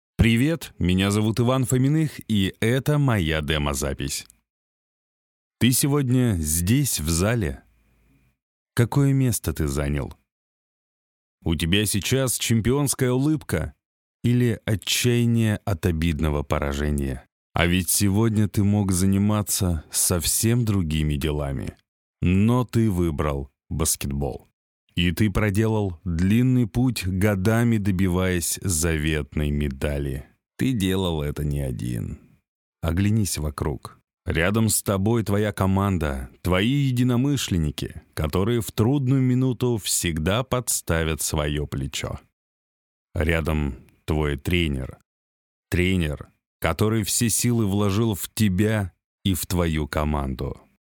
Тракт: Микрофон: Recording Tools MC-900 Пред: dbx-376 Карта: UA Apollo Twin
Демо-запись №3 Скачать